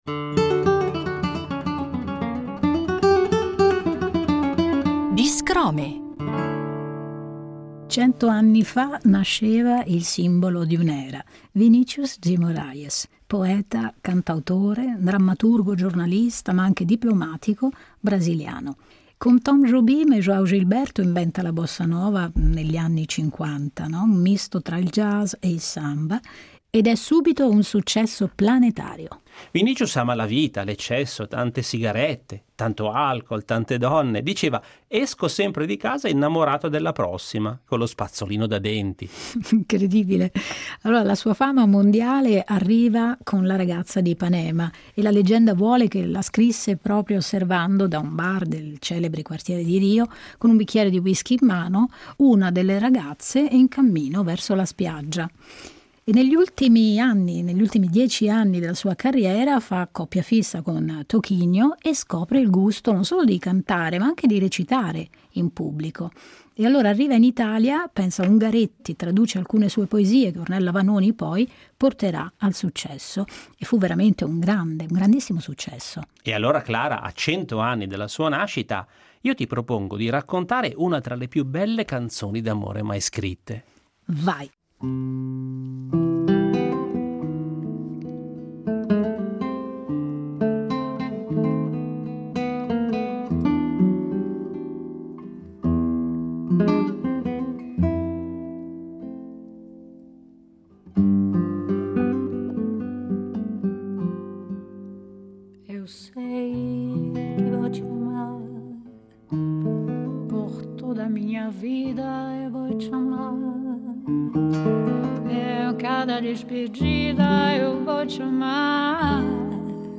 chitarrista
porta in scena nel metodo dello spoken word
una poesia orale nel battito della musica.